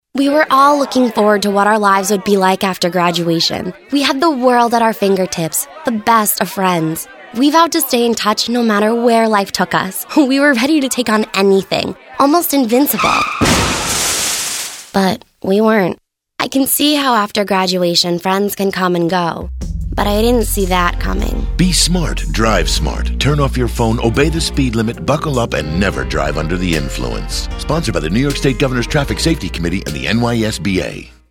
Be Smart Drive Smart :30 Radio PSA.